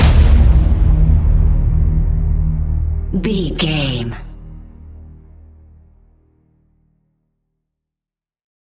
Ionian/Major
D♯
electronic
dance
techno
trance
synths
synthwave
glitch
instrumentals